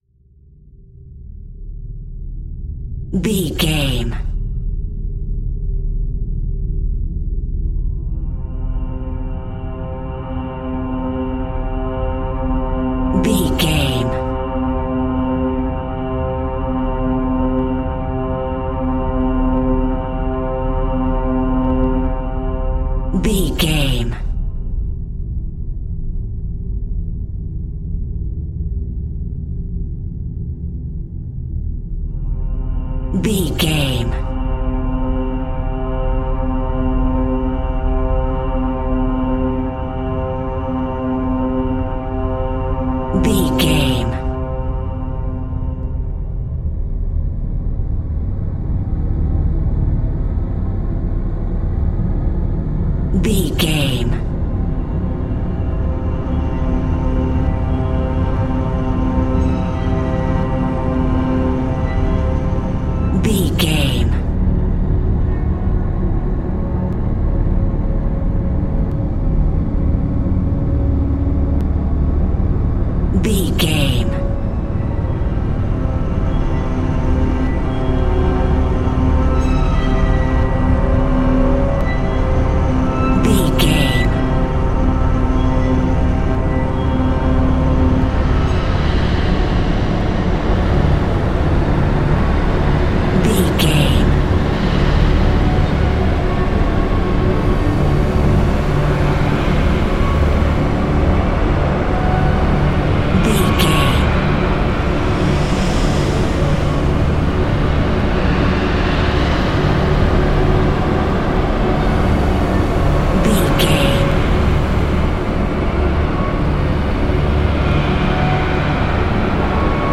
Long Ambient Build Up.
In-crescendo
Atonal
Slow
ominous
dark
suspense
eerie
synth
pads